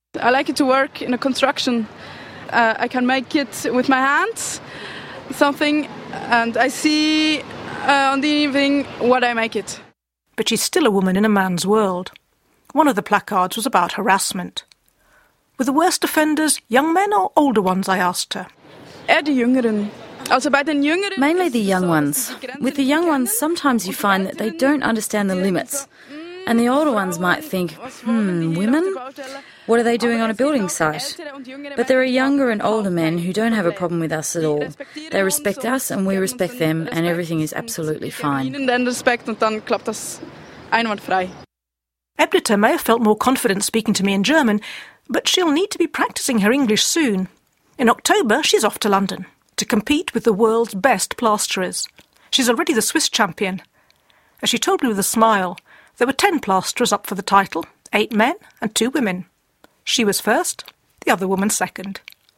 interviewed as she prepared placards for a women's demonstration in June